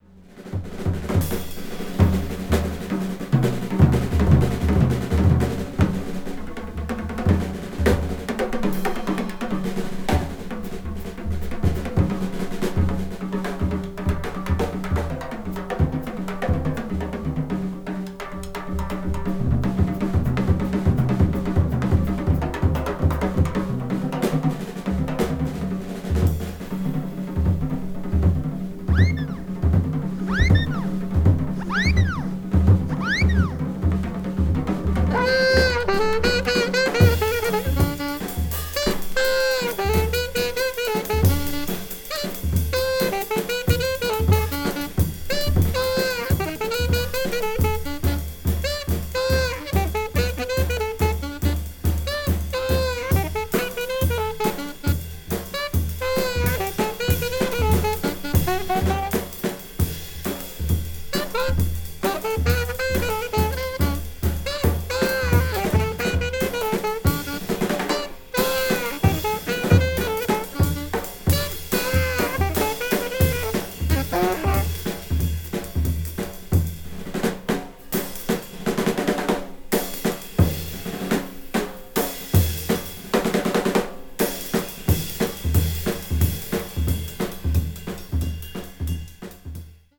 一部わずかにチリノイズが入る箇所あり
ノンストップで非常にアグレッシヴかつエネルギッシュなインタープレイを延々と続けながら